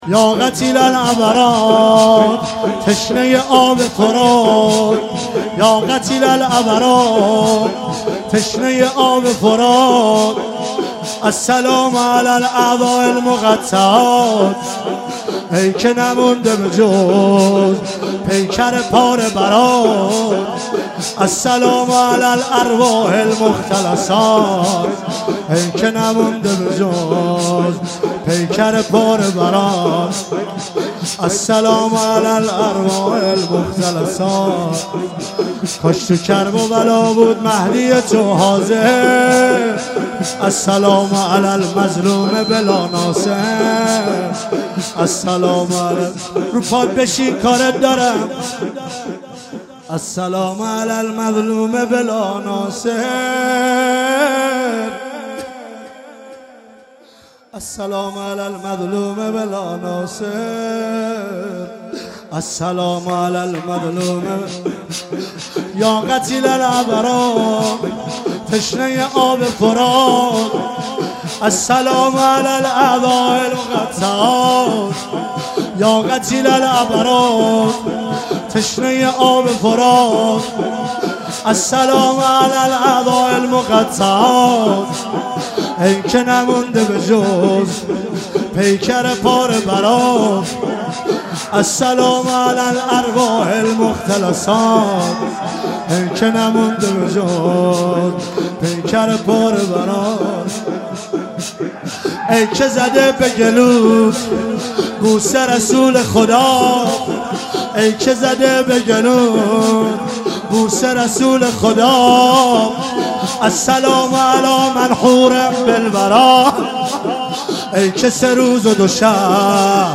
مراسم وفات حضرت معصومه ۱۳۹۶
هیئت حضرت زینب سلام الله علیها – نیشابور